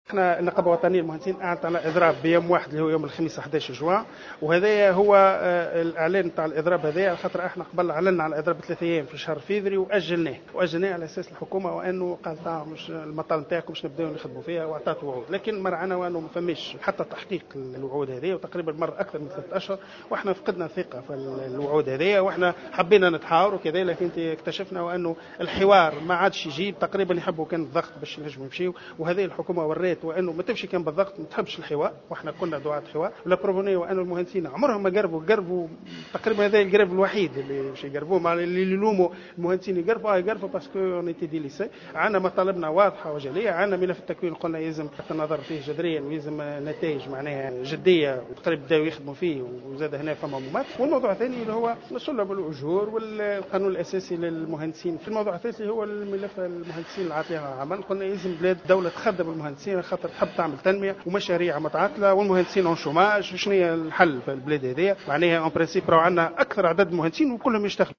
اثر ندوة صحفية انتظمت اليوم بتونس العاصمة